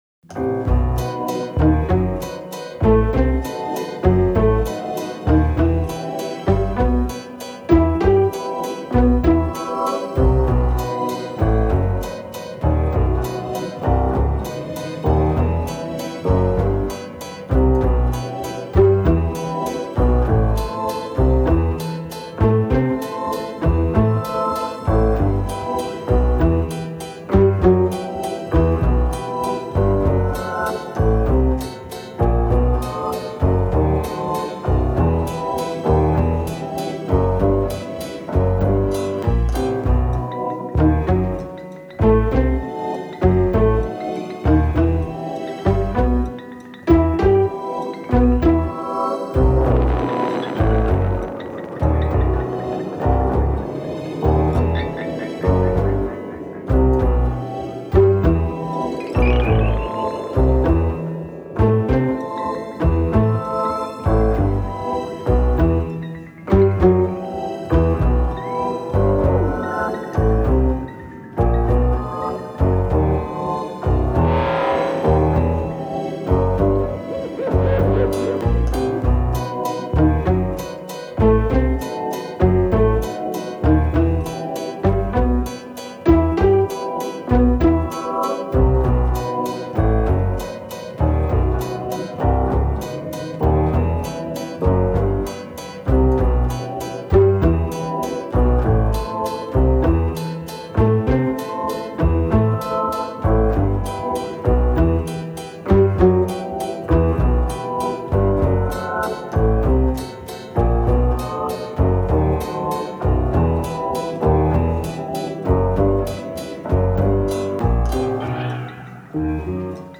Sneaky and naughty circus feel with twisted dreamlike theme.